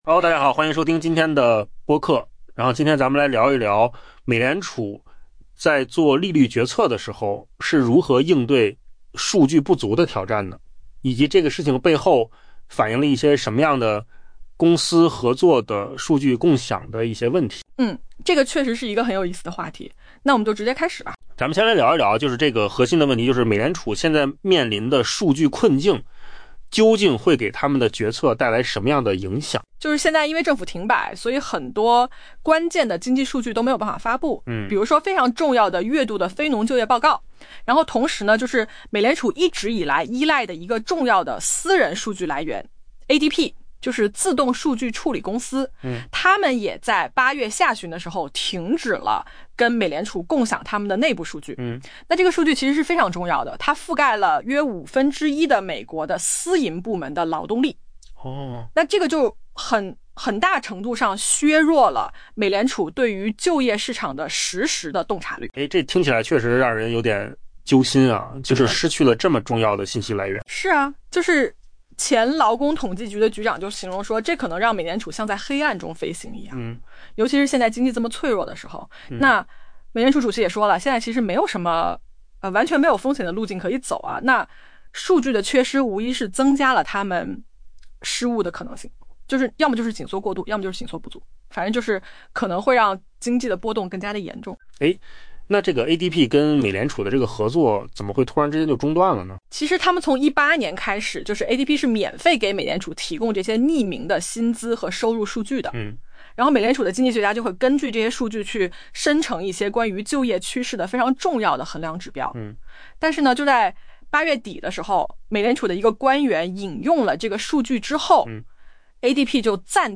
AI 播客：换个方式听新闻 下载 mp3 音频由扣子空间生成 美联储下周在准备设定利率时面临着前所未有的挑战——几乎没有可用的经济数据来支持其决策。